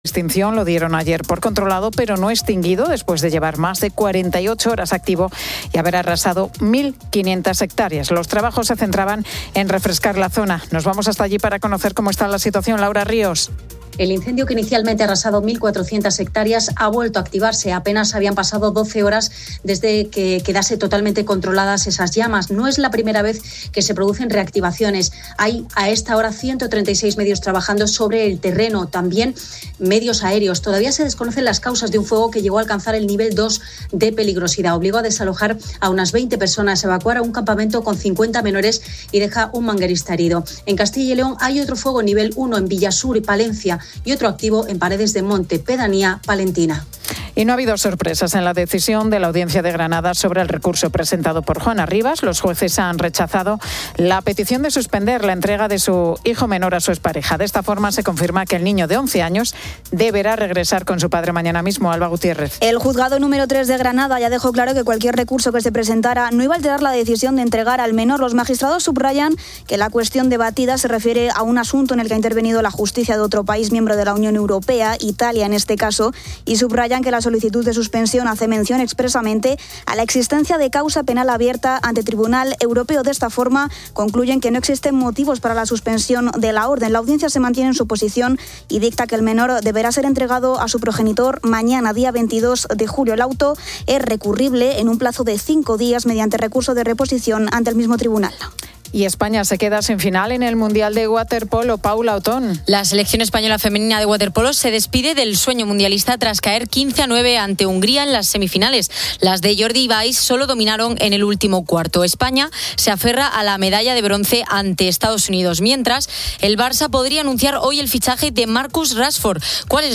entrevista a dos autónomos que comparten su experiencia personal. Además, dedica un espacio a hablar sobre todo lo que aún desconocemos del cerebro humano: emociones, enfermedades y grandes incógnitas todavía sin resolver.